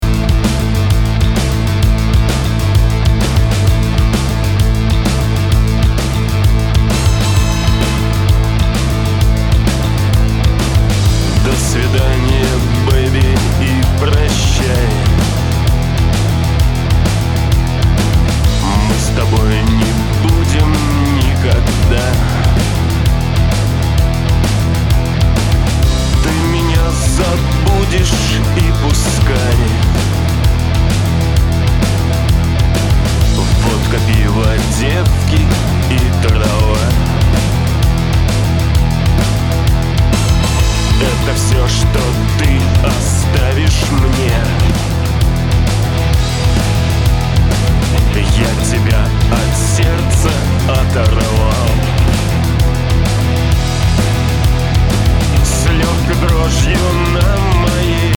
По сведению в запевах на передний план я бы выдвинул и подчеркнул басовую партию, а пэд бы приглушил или вовсе убрал, а вот в припеве можно бы и пэд. Вокал особенно в припевах стоило бы чуть громче. Ну и про механичный хэт уже было сказано